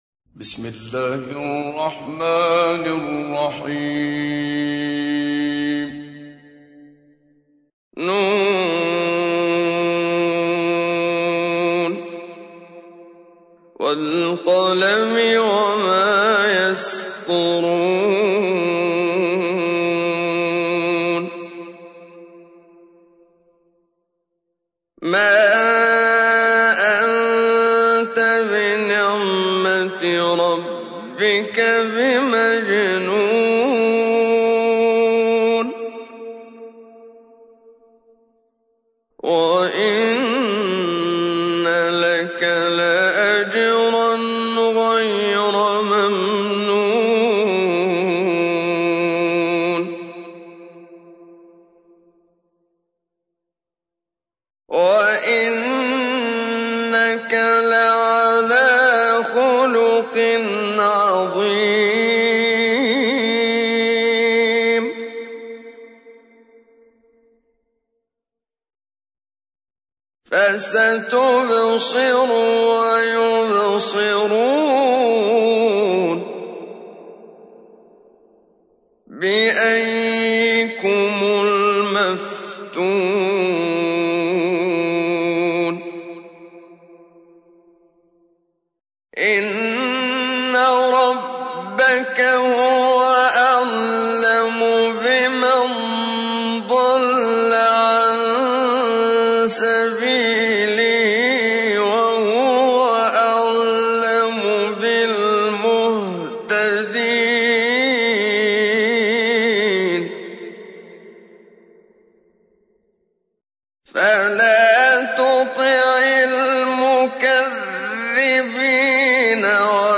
تحميل : 68. سورة القلم / القارئ محمد صديق المنشاوي / القرآن الكريم / موقع يا حسين